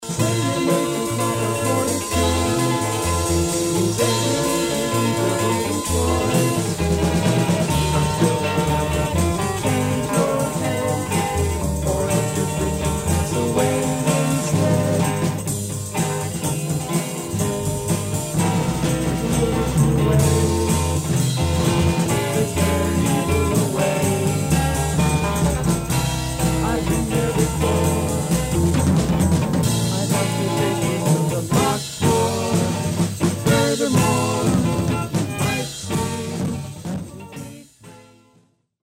early PS demo